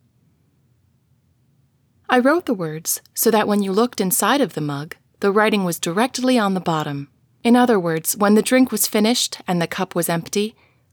I’ve found most of what I needed, but am still unfortunately failing the noise floor requirements from ACX Check.
I use an Audio Technica AT2020 USB (I know, not ideal) and a pop filter, and I am in a very quiet room, I think. I’ve read that using Noise Reduction isn’t recommended, but when I DO try it, based on the specifications you list in this thread (the 666 and the 966), then THIS clip passes, but other sections of my recording fail.